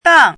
chinese-voice - 汉字语音库
dang4.mp3